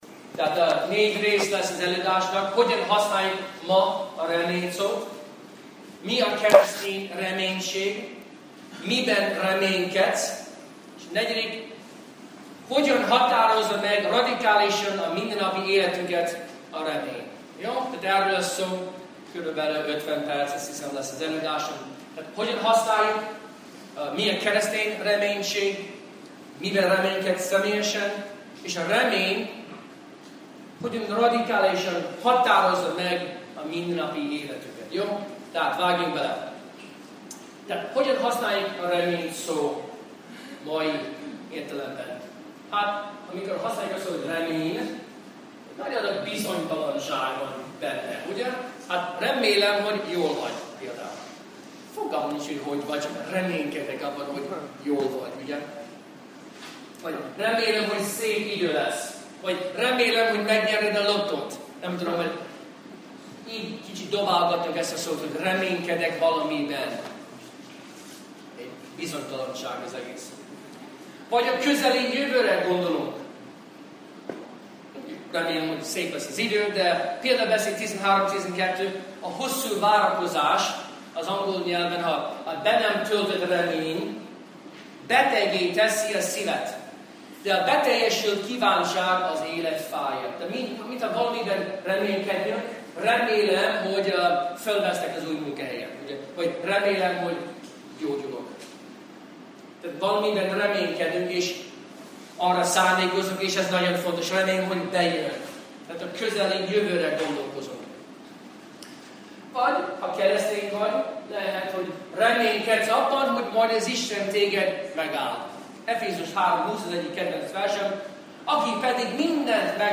Ha úgy érzed, nincs reményed, hallgasd meg az előadást!